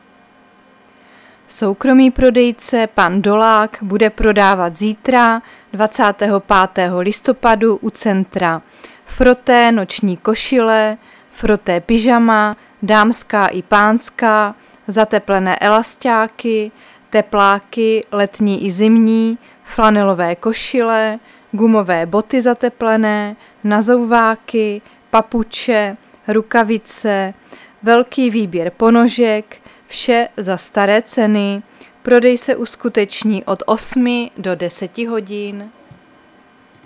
Záznam hlášení místního rozhlasu 24.11.2022